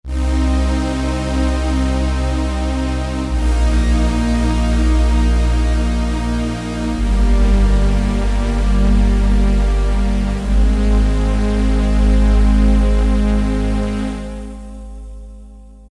ROLAND JD-800
jd800_jmj_pad.mp3